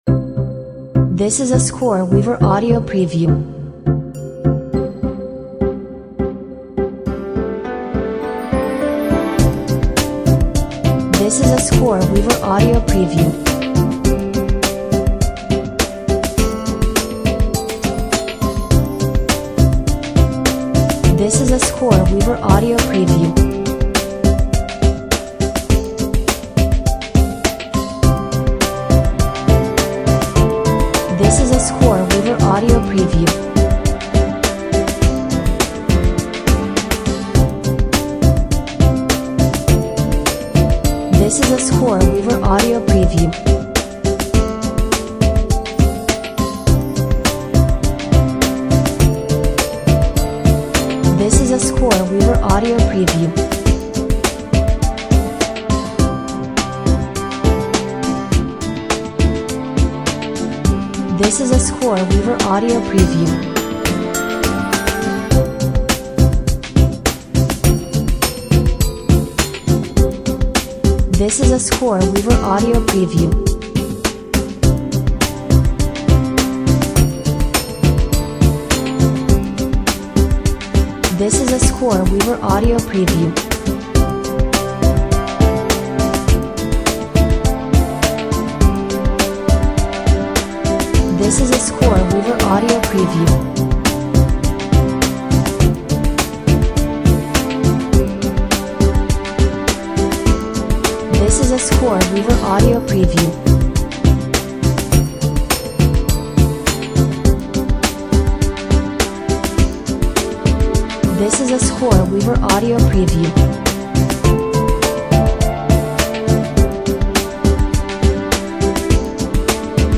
Inspiring and magical Christmas Music, upbeat and positive!